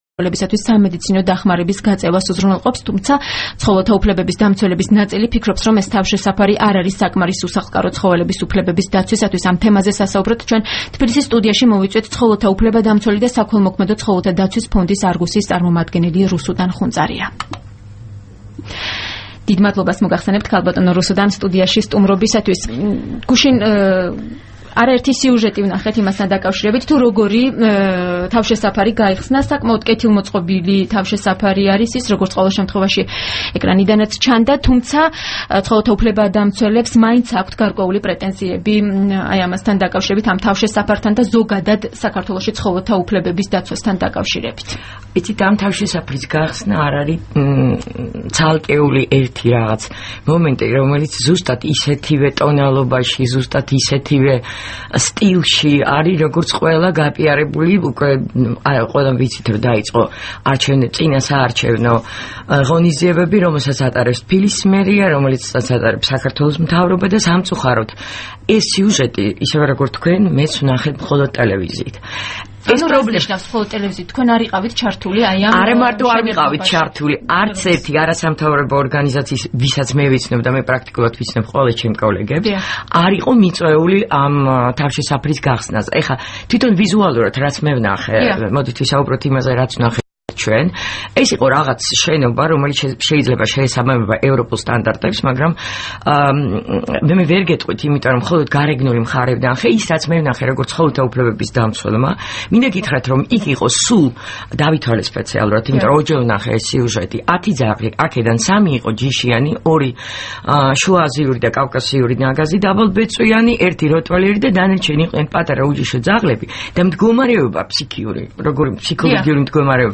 რადიო თავისუფლების თბილისის სტუდიაში